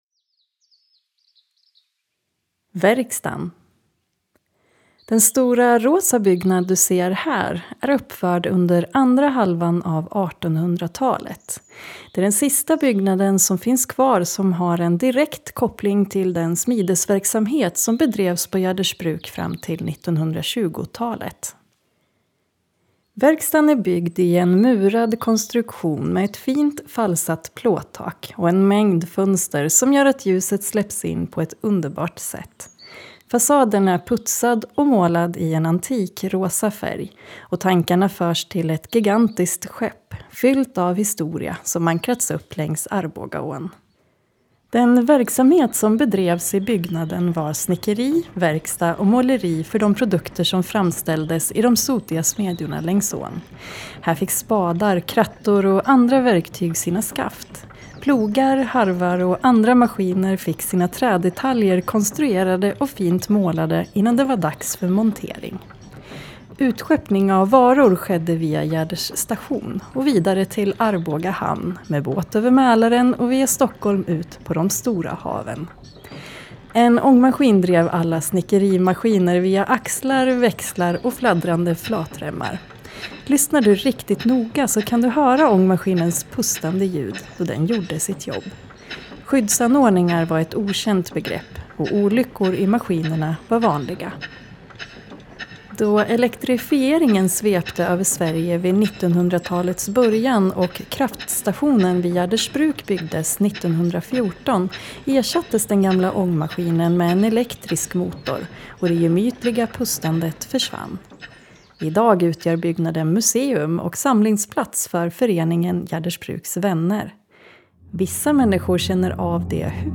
Lyssnar du riktigt noga så kan du höra ångmaskinens pustande ljud då den gjorde sitt jobb.